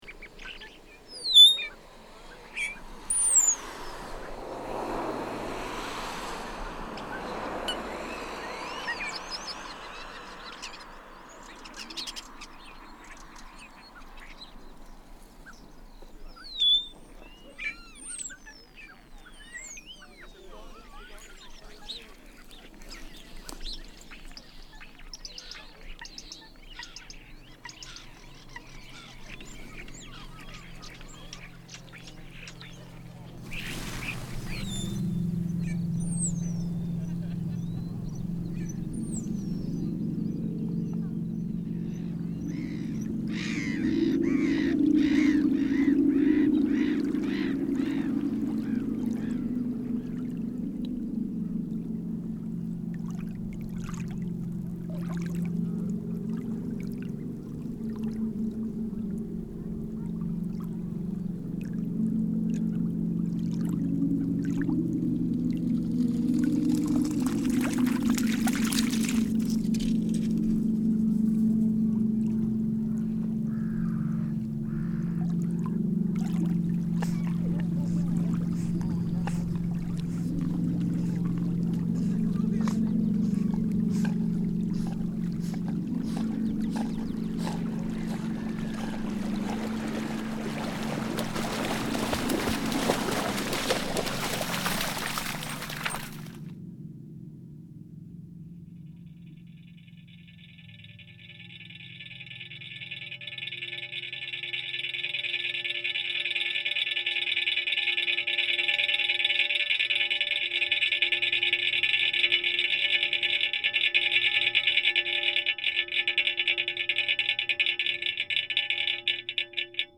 Eight soundscapes from the south of England
Recording has become a habit, a method of engaging with the world around me. I use an array of contact microphones, hydrophones and other microphone technologies and recording methods, to help me listen in on the acoustic environment.
Arriving in the car park at Hengistbury Head, I am greeted with a radiophonic static chorus of Starlings. Their song signals my arrival within a spectral landscape: a place between stations, continually ebbing in and out of existence. In the sheltered quiet of the landscape behind the Head emerges an ethereal harmonic hum, as the sea breeze brushes through a wire fence.
A dog running through the lap of the salt marsh shore, leaves a wet stereophonic tear in the tidal two and fro as the approaching meditative hum of the land train drags a trace of human presence across the landscape. Sunk beneath the beach a hydrophone offers a Geiger like calculation of each sonic particle of sand. As rain clouds approach and the air pressure drops a contact microphone finds a single wing nut at the top of an empty flagpole, pealing out an unheard yet clangorous alarm.
hengistbury_head_2min.mp3